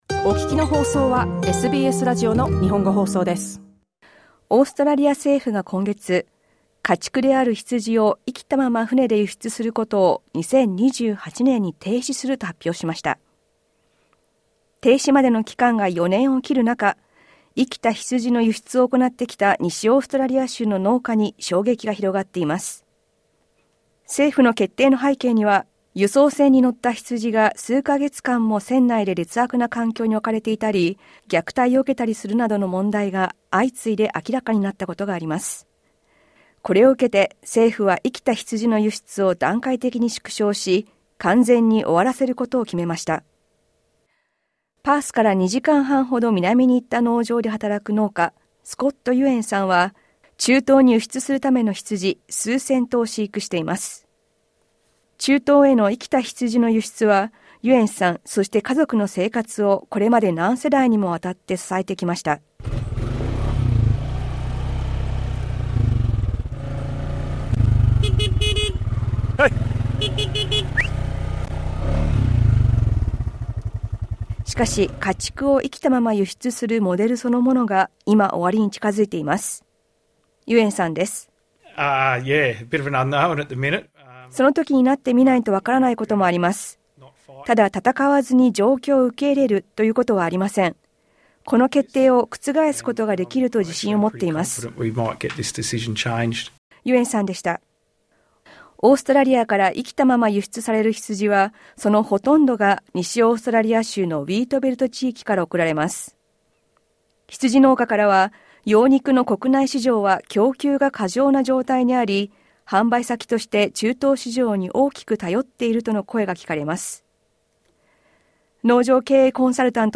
詳しくは音声リポートからどうぞ。